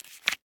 card_swipe.ogg